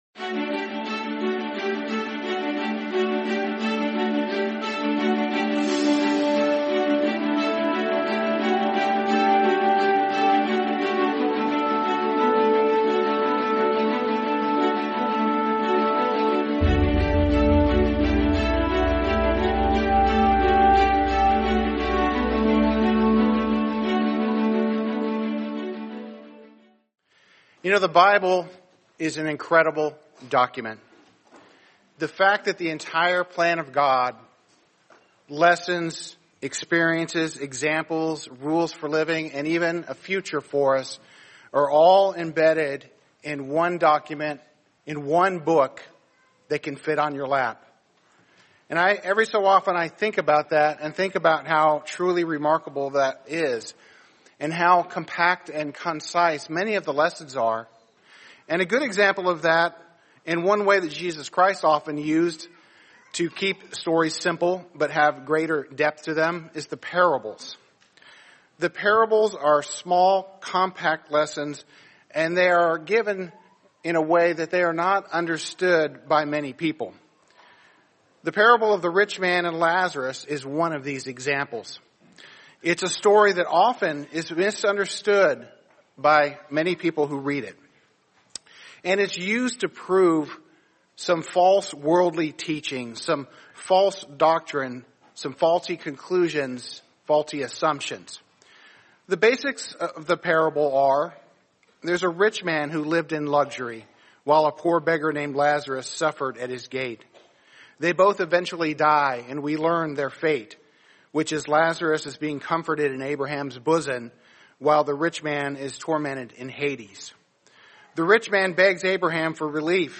The Parable of Lazarus and the Rich Man | Sermon | LCG Members